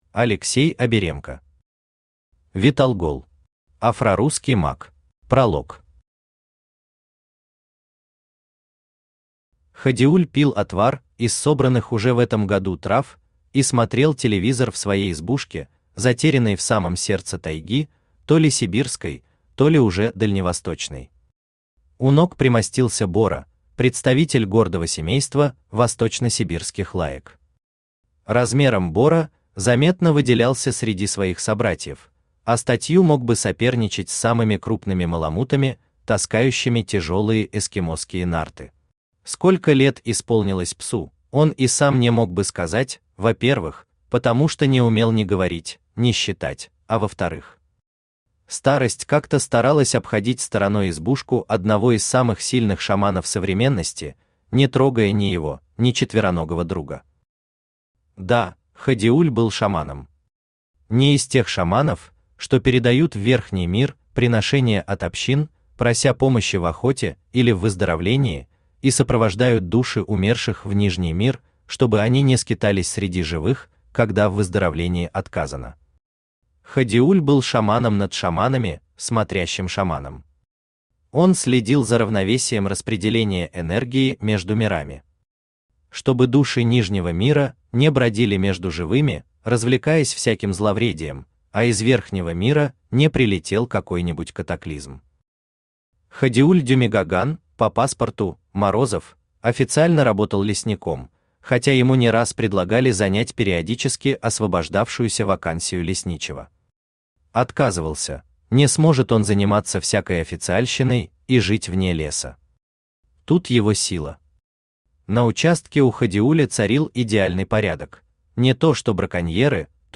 Аудиокнига Виталгол. Афрорусский маг | Библиотека аудиокниг
Афрорусский маг Автор Алексей Евгеньевич Аберемко Читает аудиокнигу Авточтец ЛитРес.